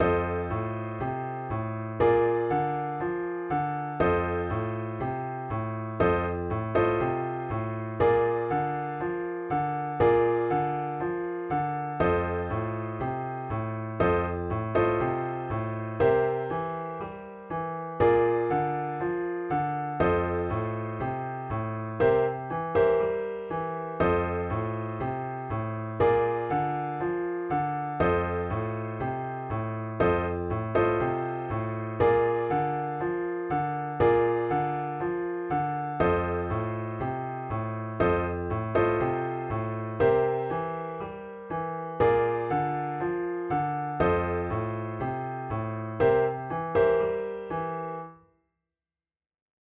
Here is the same blues in the key of F as in the example on blues basics, but this time played with dominant seventh chords. The F7 and G7 chords are played not in root position, but as inversions.
Blues with seventh chords in the key of F
Note that in bar 2 the IV chord (Bb) is played.
blues_with_seventh_chords_F.mp3